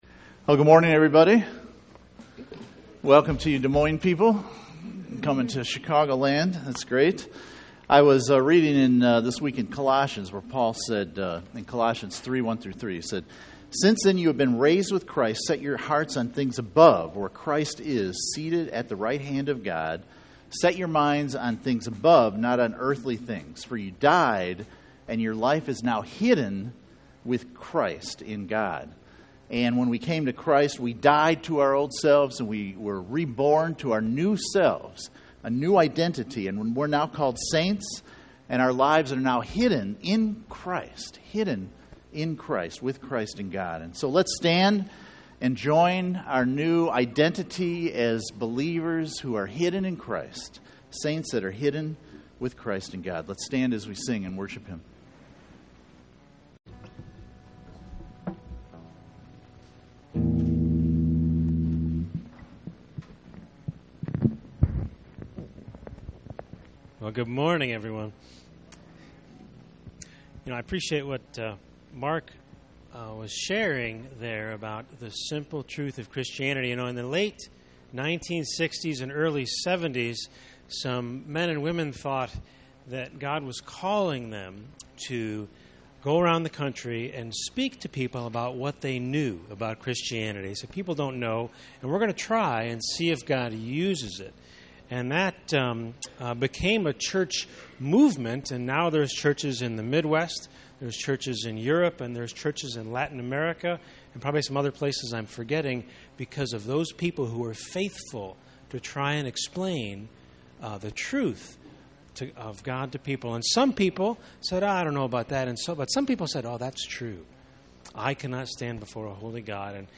Spiritual Identity Service Type: Sunday Morning %todo_render% « Spiritual Well Being 1 Spiritual Communication